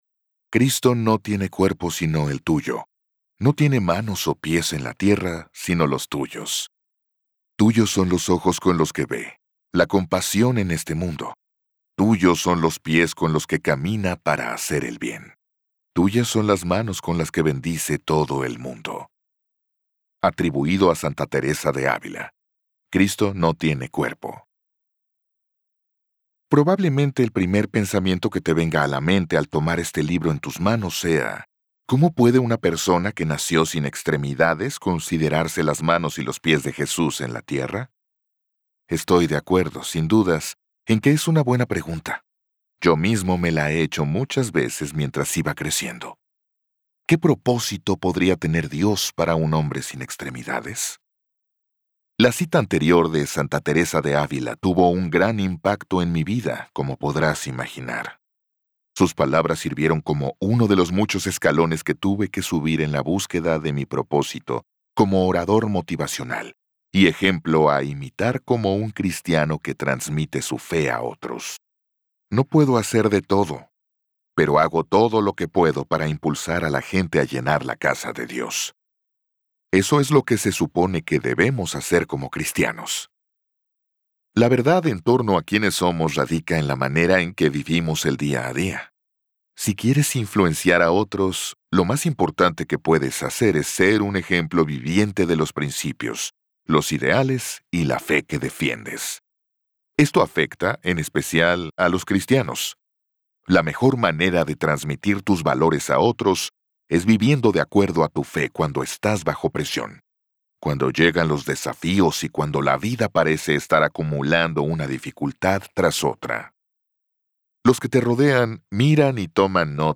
Se las manos y los pies de Cristo Audiobook
Narrator
9.1 Hrs. – Unabridged